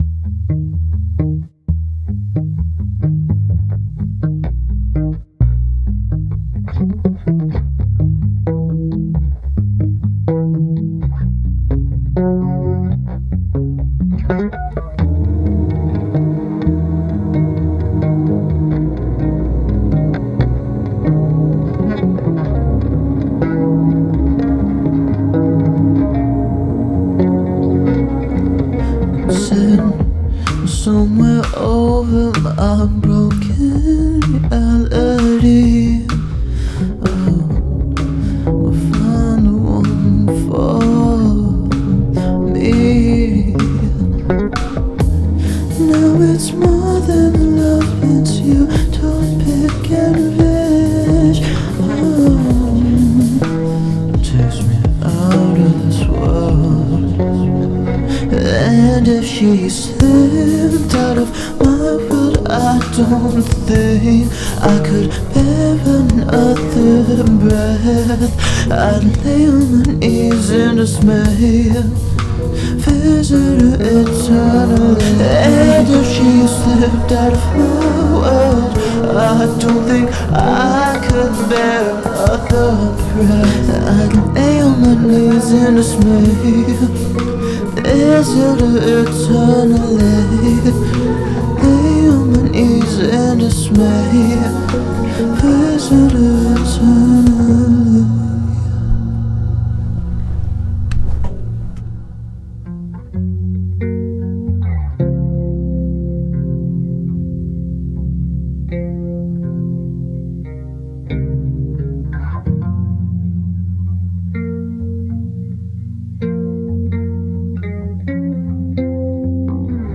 Raw, fast, and full.